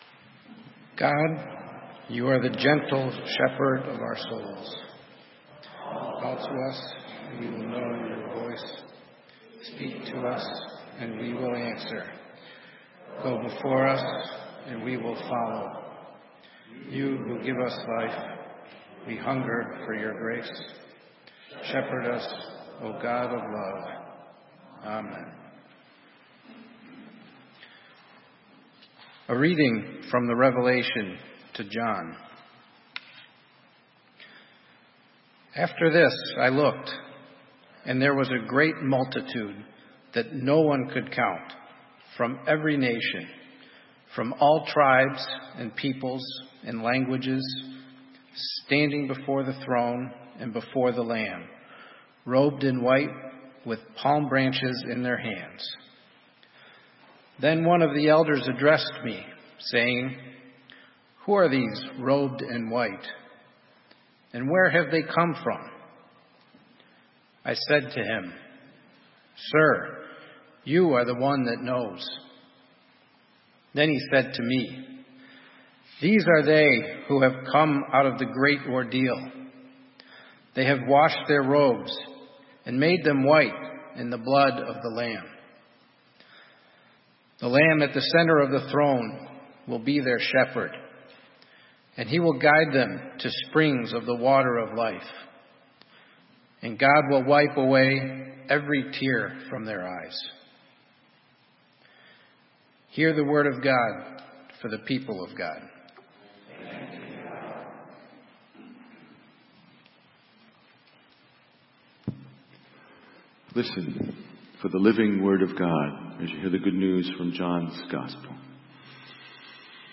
Sermon:Following the voice - St. Matthew's UMC